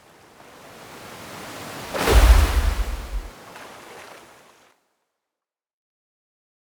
water sword Buff 7.wav